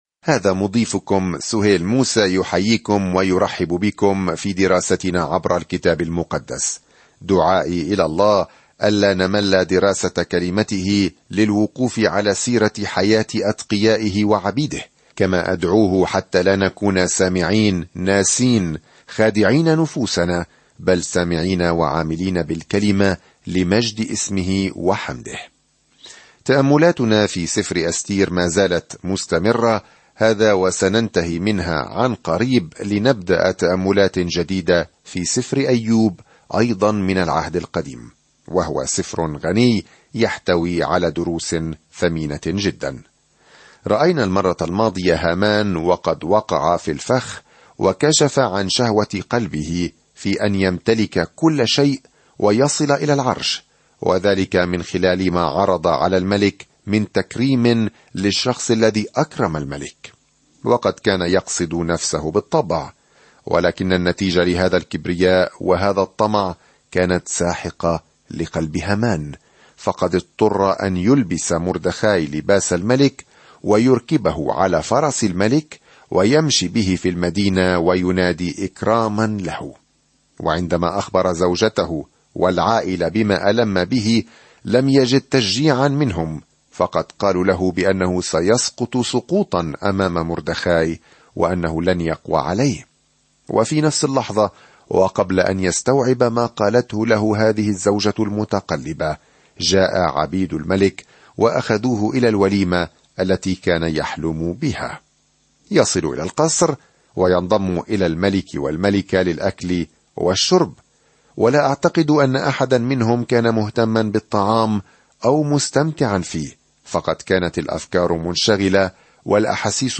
سافر يوميًا عبر إستير وأنت تستمع إلى الدراسة الصوتية وتقرأ آيات مختارة من كلمة الله.